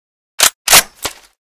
unjam.ogg